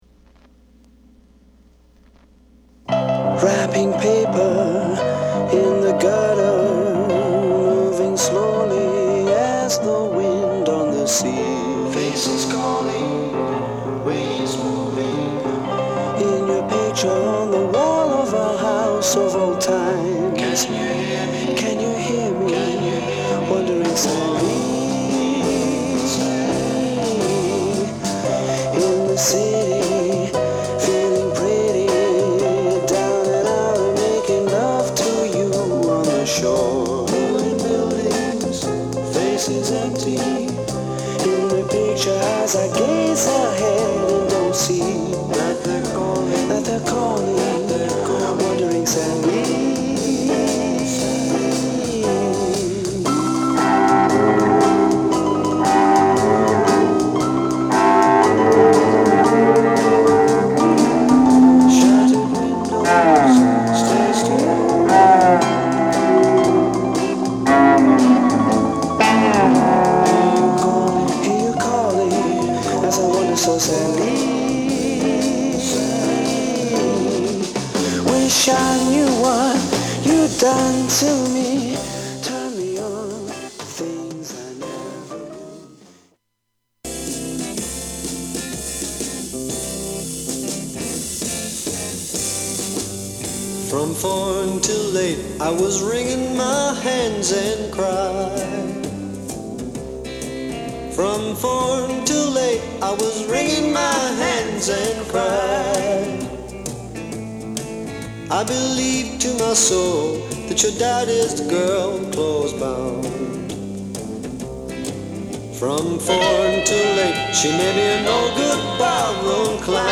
ROCK / BRITISH ROCK / BLUES
盤は薄い擦れや僅かですが音に影響がある傷がいくつかある、使用感が感じられる状態です。